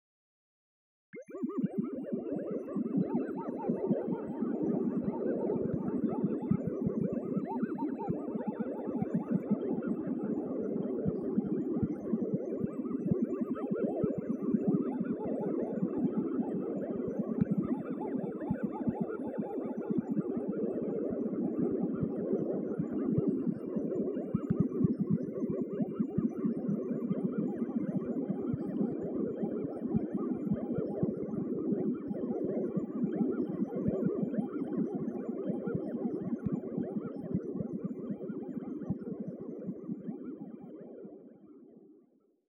magic-bubbles.wav